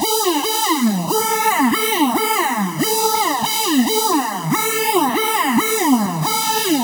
VEE Melody Kits 23 140 BPM Root A.wav